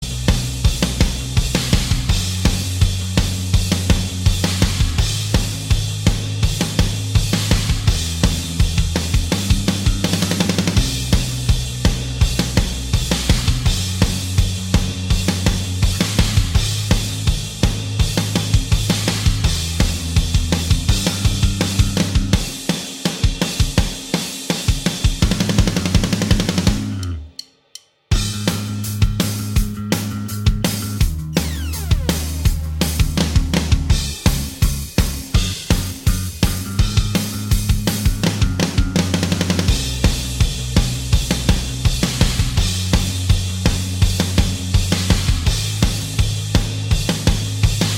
Minus All Guitars Indie / Alternative 3:25 Buy £1.50